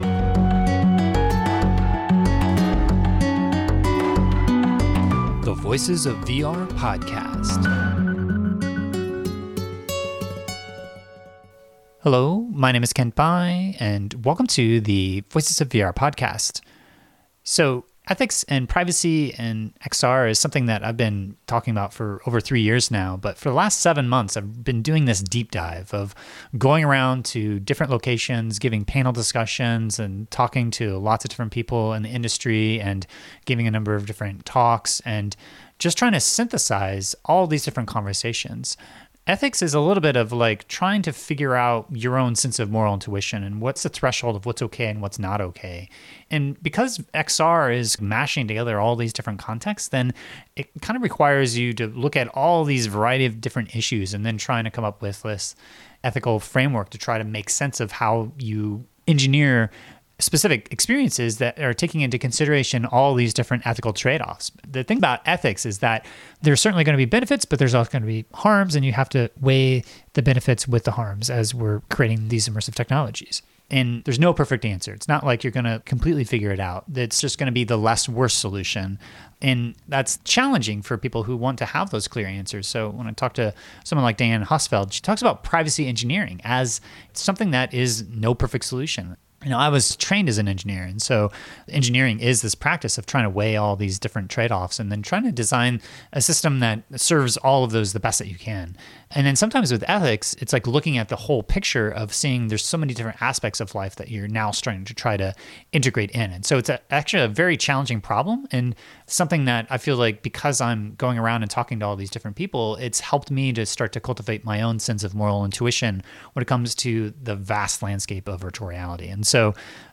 #832 XR Ethics: SXSW Panel on XR Ethics – Voices of VR Podcast
Voices-of-VR-832-SXSW-XR-Ethics-Panel.mp3